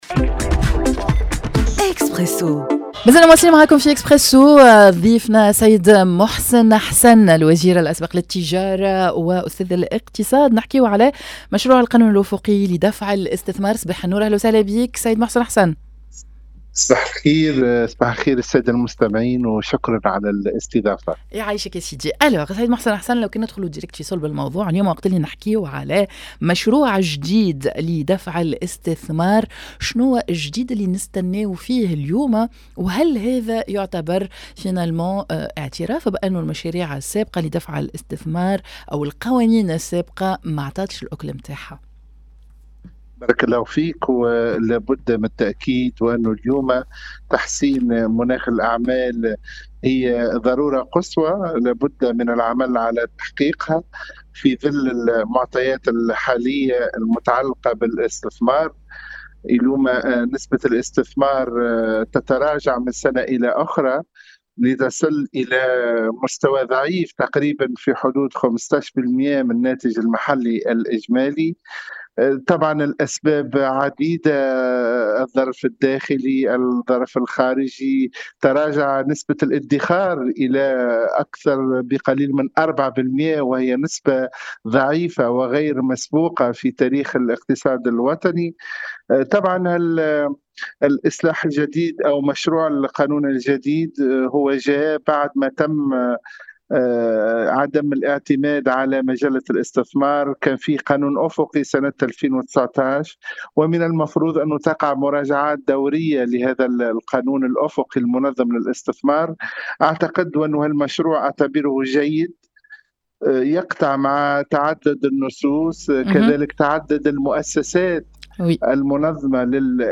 مشروع القانون الأفقي لدفع الإستثمار التفاصيل مع أستاذ الإقتصاد و وزير التجارة الأسبق محسن حسن